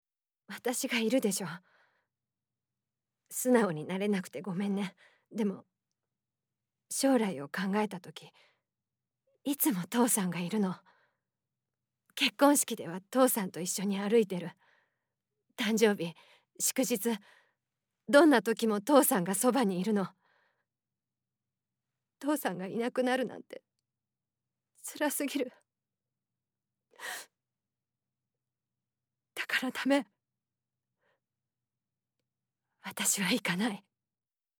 セリフ@↓